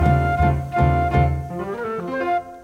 This is a flute stinger instrumental with a drop to it.